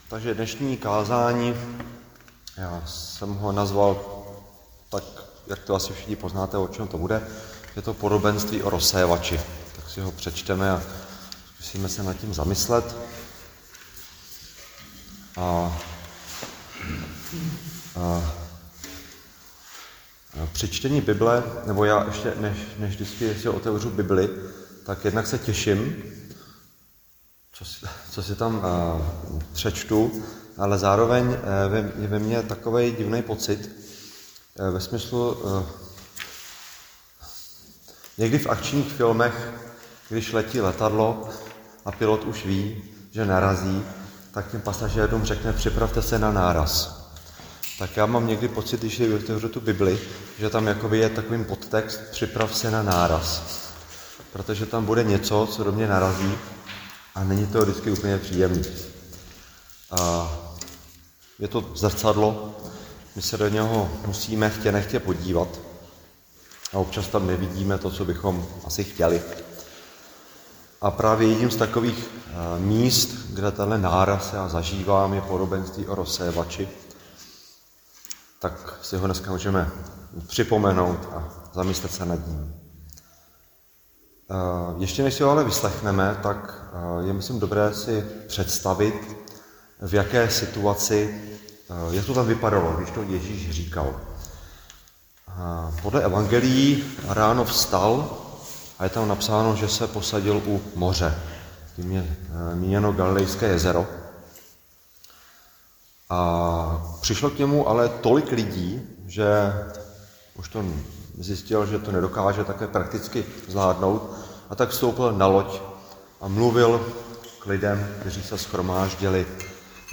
Křesťanské společenství Jičín - Kázání 23.2.2025